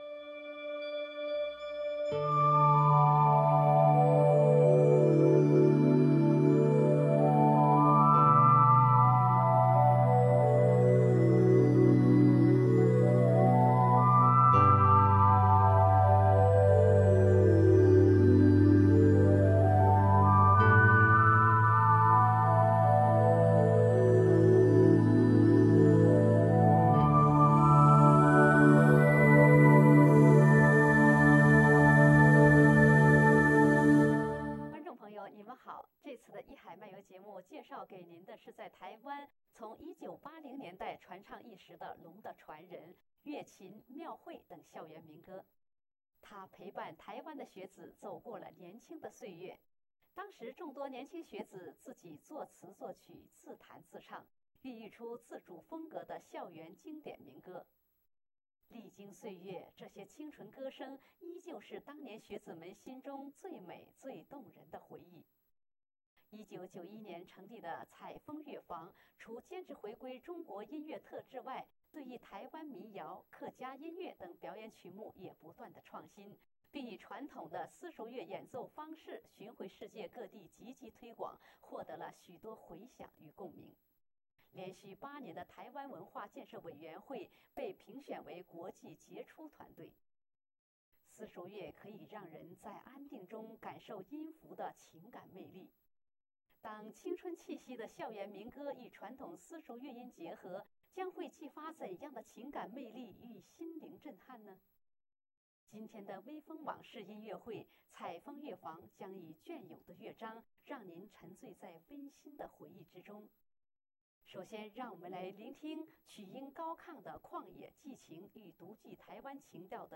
自彈自唱